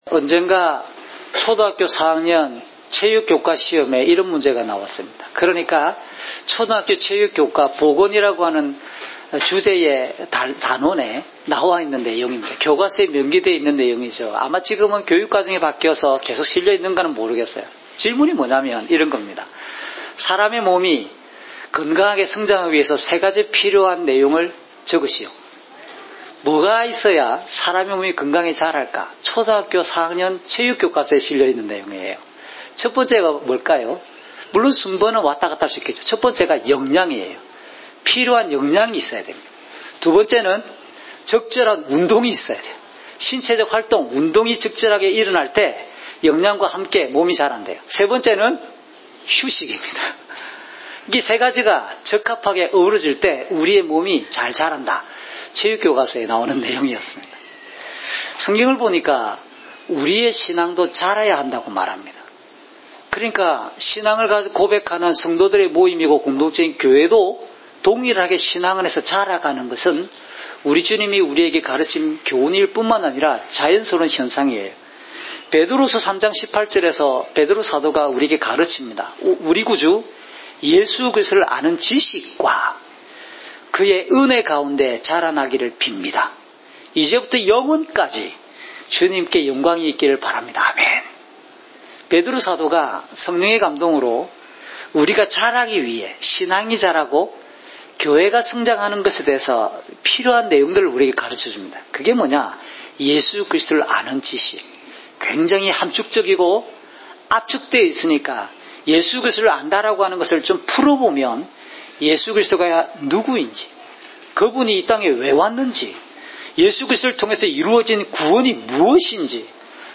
주일설교 - 2019년 11월 24일 “교회는 함께 자라가야 합니다!"(엡4:1~16)